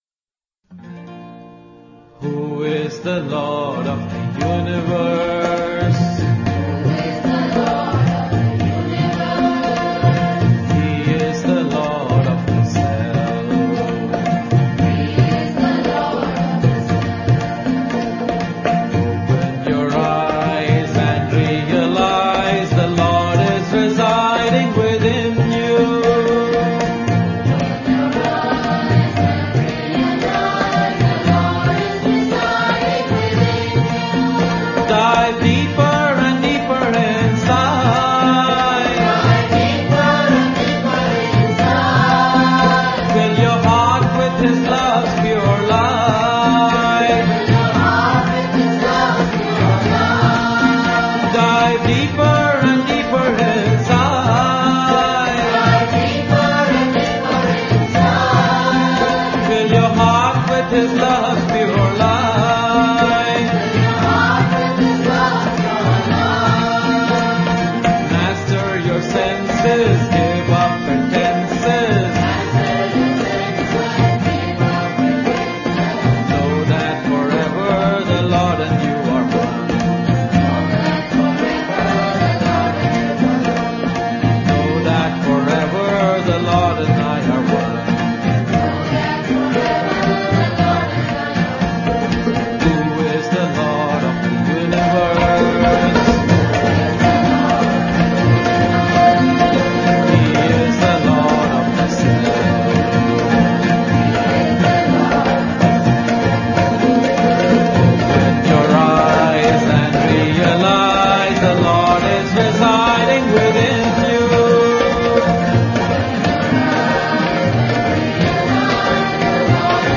1. Devotional Songs
Major (Shankarabharanam / Bilawal)
8 Beat / Keherwa / Adi
Medium Fast